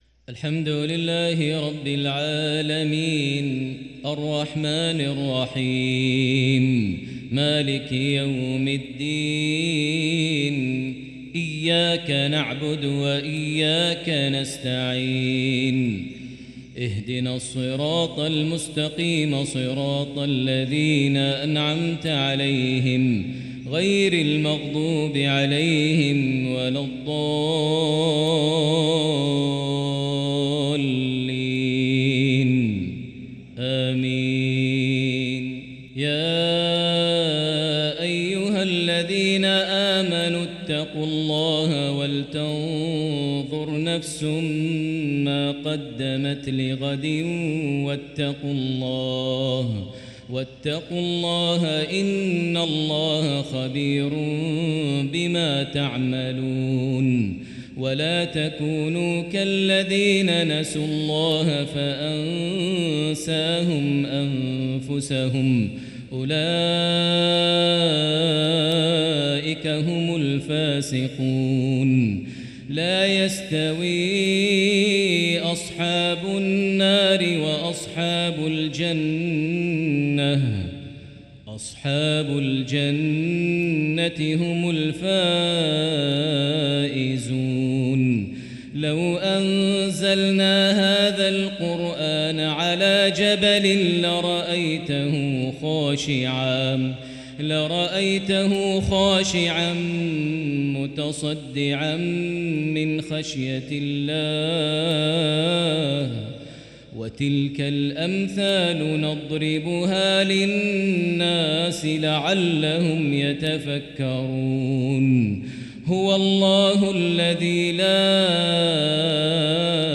مغربية كردية رائعة للشيخ ماهر المعيقلي - 19 شعبان 1444هـ